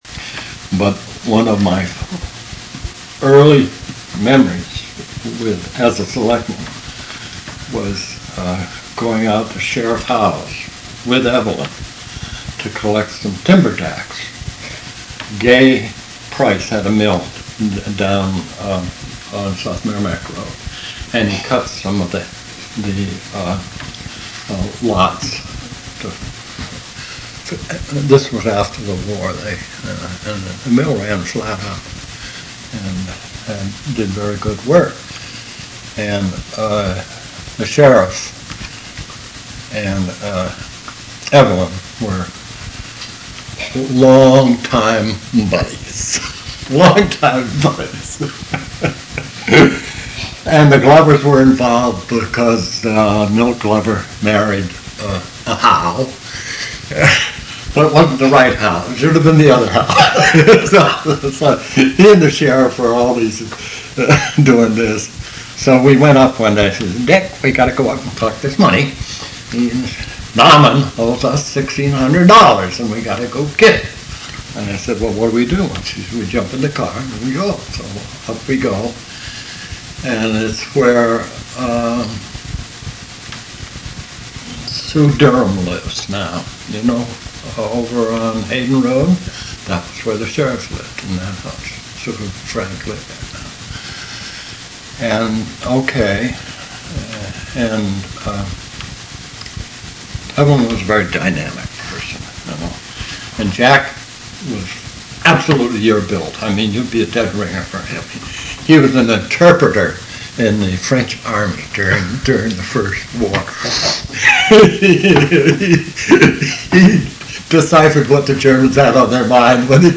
ORAL HISTORY SAMPLE
interview of Dick Walker , long time Selectman, telling about an early experience when the Selectmen collected the taxes.